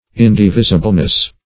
Search Result for " indivisibleness" : The Collaborative International Dictionary of English v.0.48: Indivisibleness \In`di*vis"i*ble*ness\, n. The state of being indivisible; indivisibility.
indivisibleness.mp3